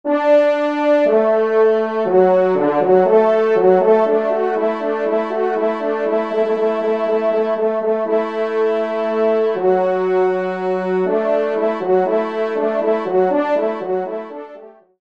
Pupitre 3°Trompe